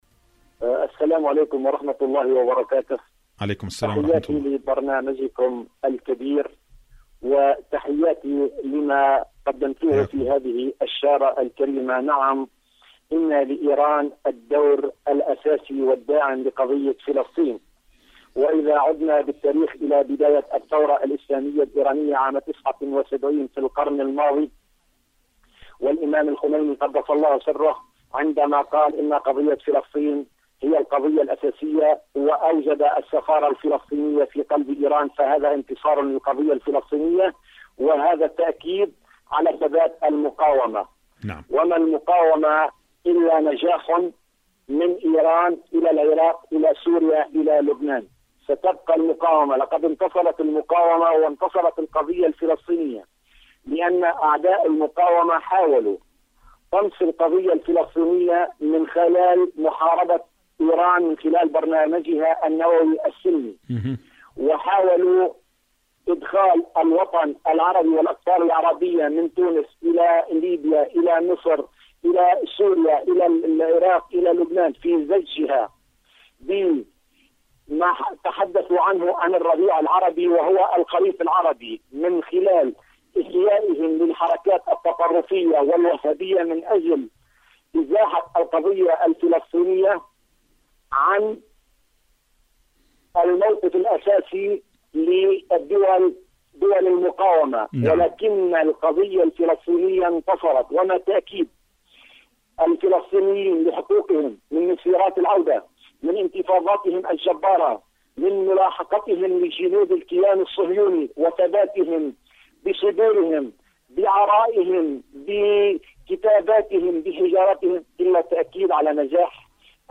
برنامج: أرض المقاومة / مشاركة هاتفية